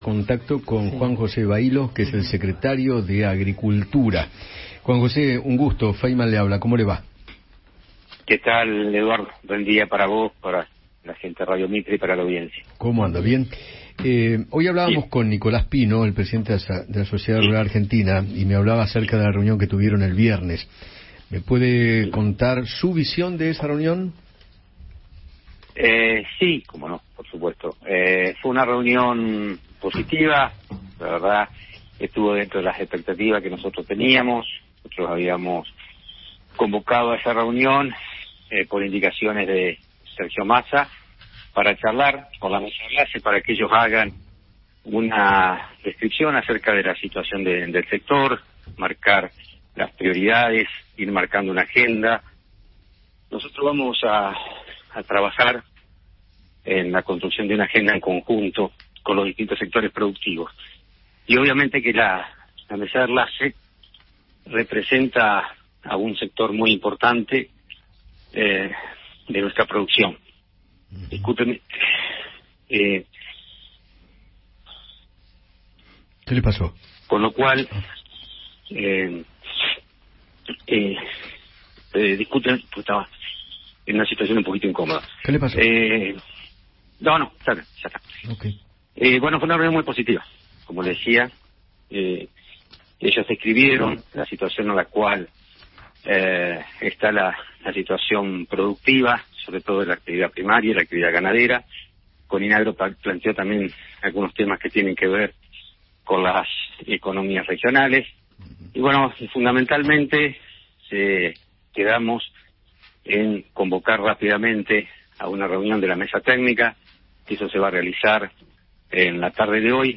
Juan José Bahillo, secretario de Agricultura, conversó con Eduardo Feinmann sobre la reunión que mantuvo con representantes del sector agroindustrial el día viernes.